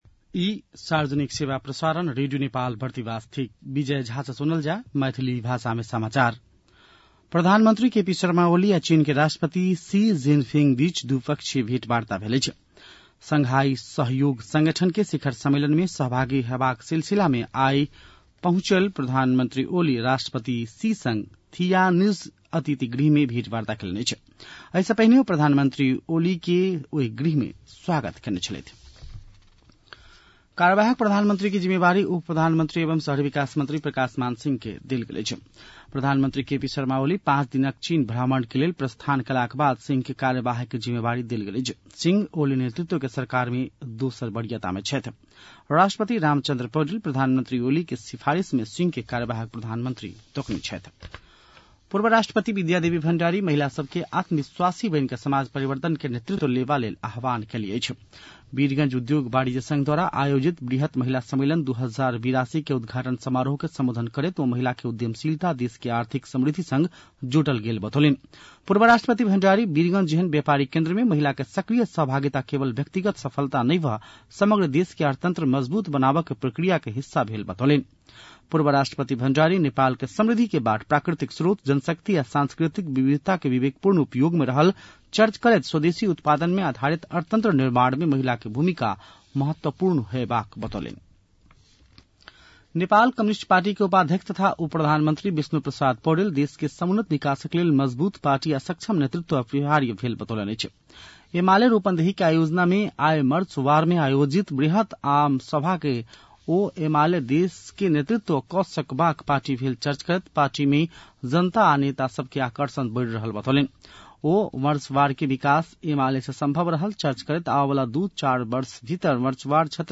मैथिली भाषामा समाचार : १४ भदौ , २०८२